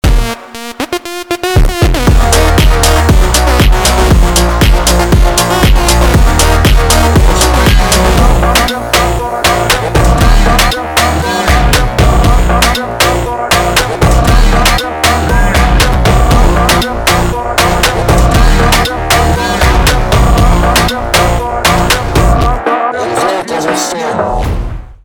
фонк
битовые , басы , качающие , жесткие